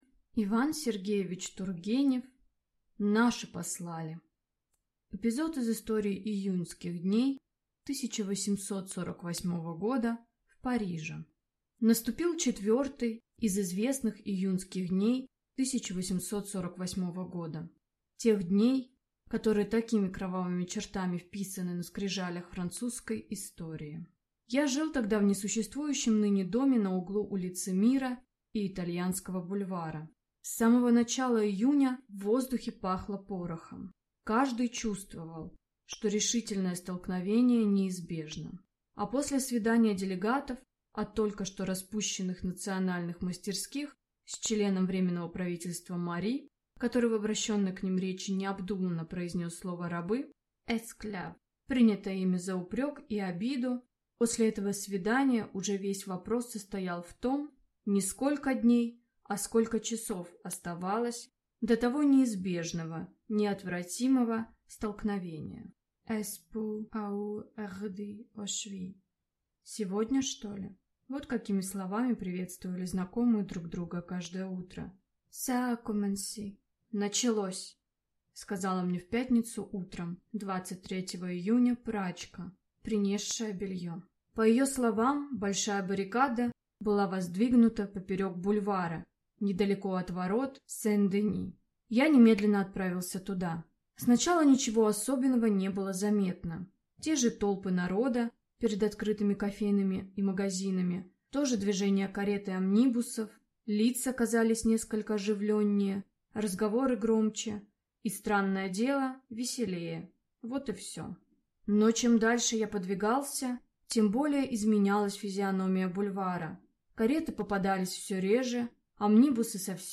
Аудиокнига Наши послали!